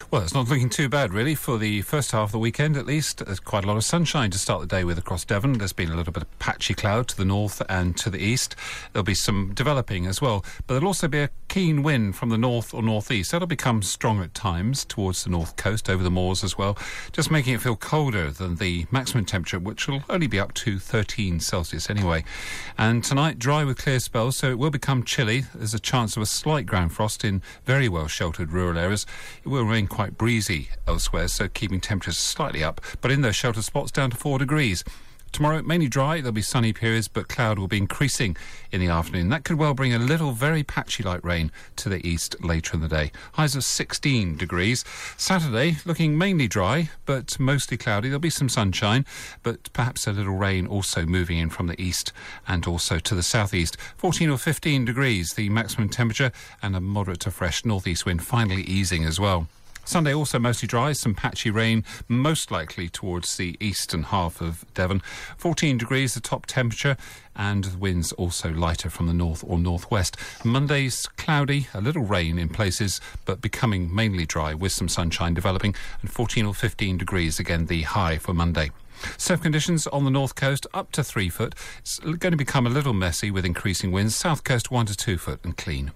5 day forecast for Devon from 8.35AM on 10 October